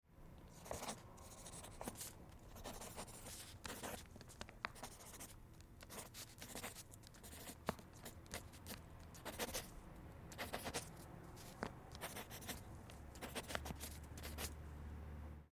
Download Swish sound effect for free.
Swish